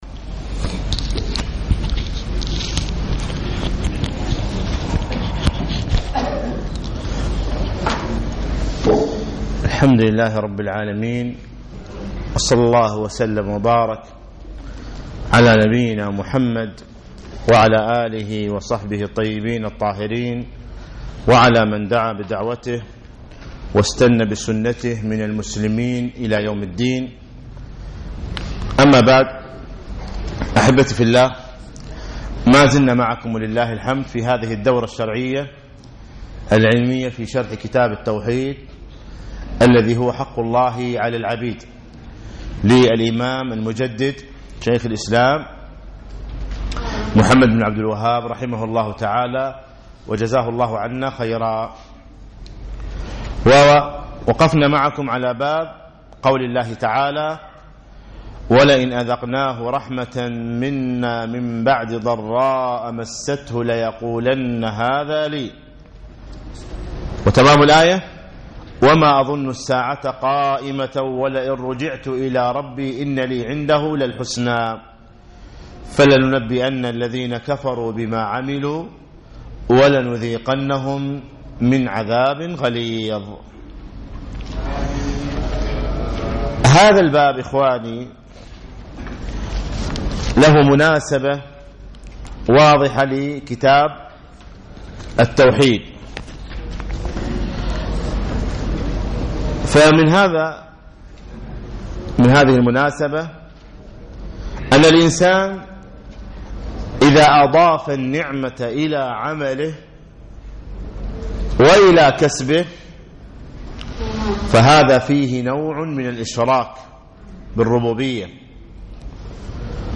يوم الثلاثاء 1 9 2015 بعد صلاة المغرب بمسجد عطارد بن حاجب